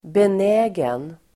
Uttal: [ben'ä:gen]